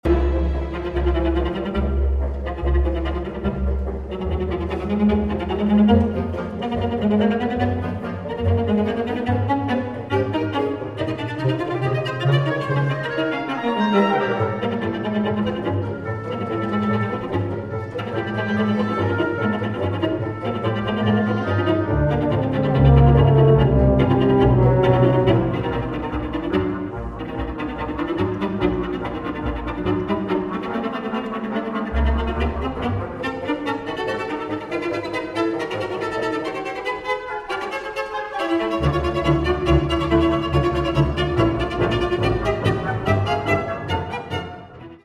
Doppio movimento – (4:33)